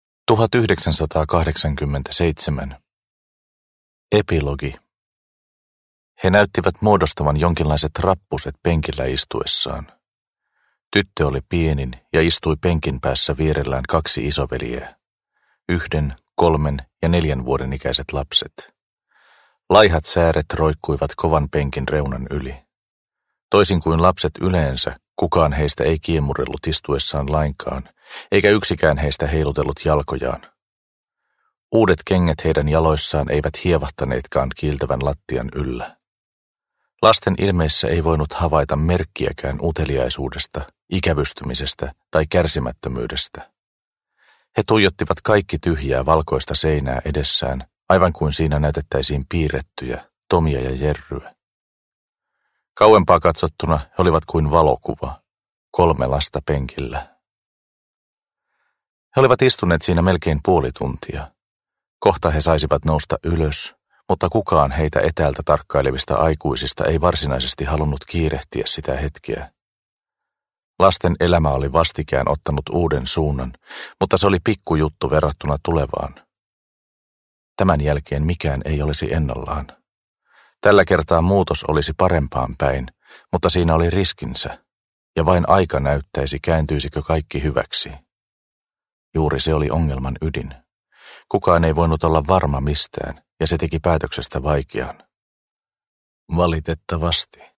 Perimä – Ljudbok – Laddas ner